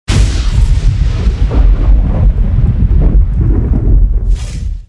Звук грозы/thunder 20 сент. 2023 г.
Звук грозы и молнии